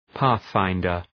{‘pæɵ,faındər}